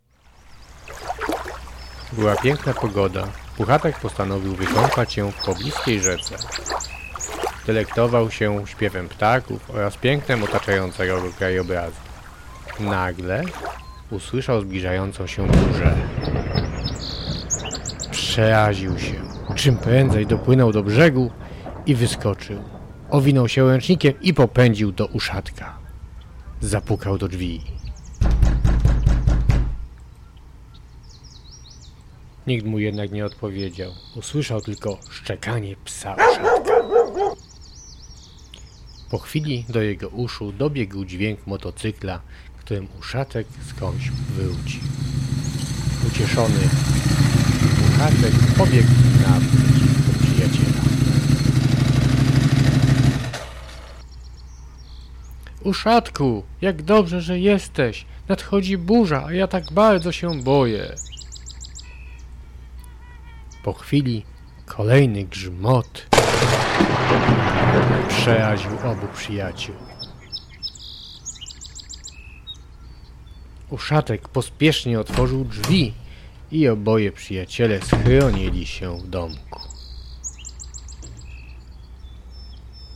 słuchowisko.mp3